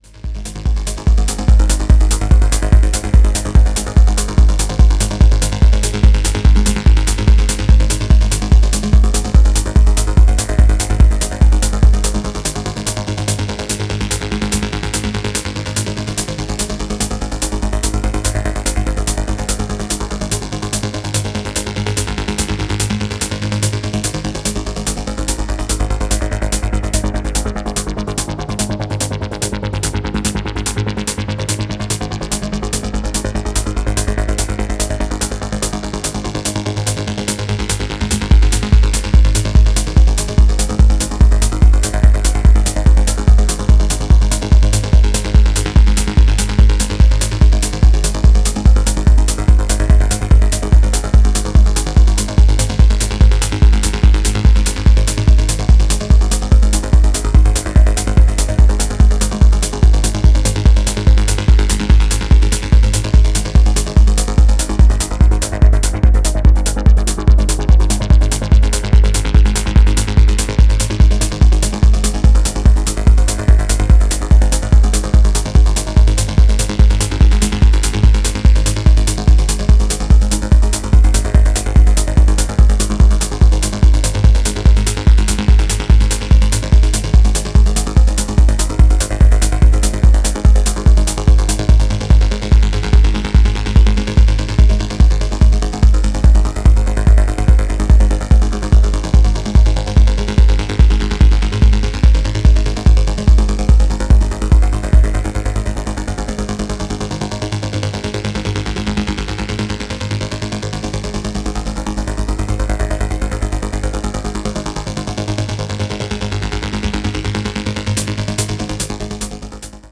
金縛り強磁界アルペジオな